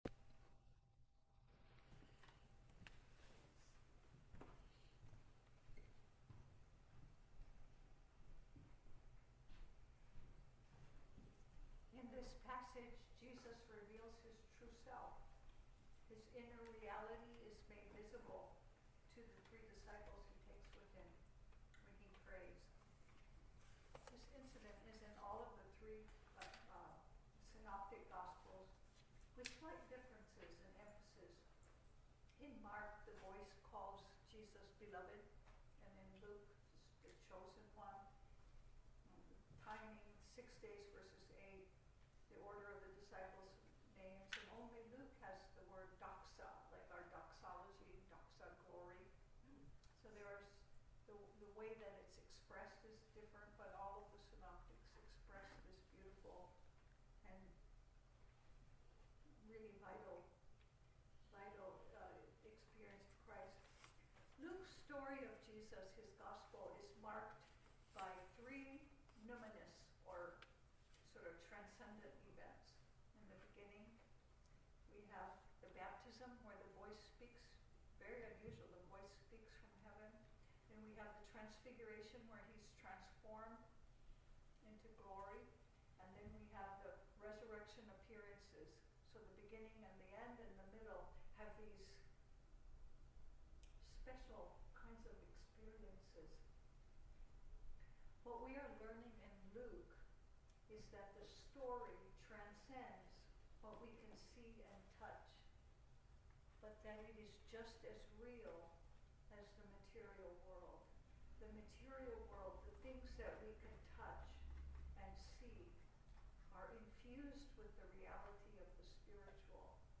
Luke 9:28-36 Service Type: Sunday Service Spiritual formation is making what we learn of God central to our lives.